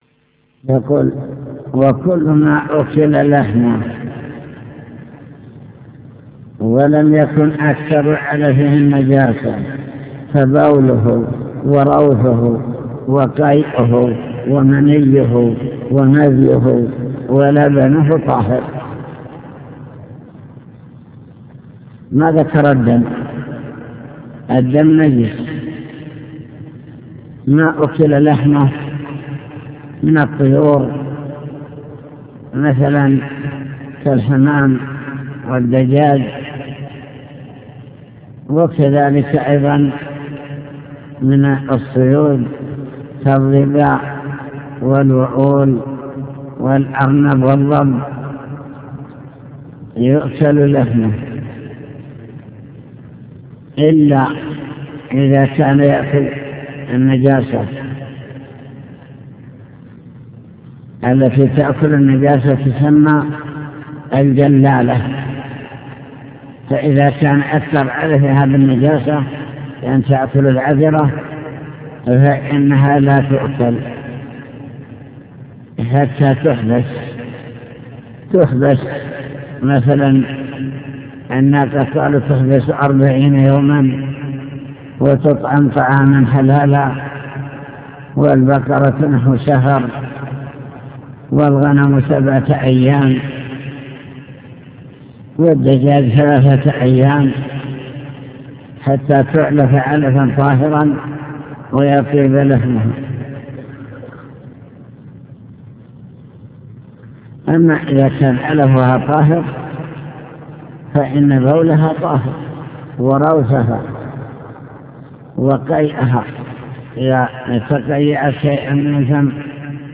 المكتبة الصوتية  تسجيلات - كتب  شرح كتاب دليل الطالب لنيل المطالب كتاب الطهارة باب إزالة النجاسة